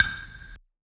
TS - PERC (6).wav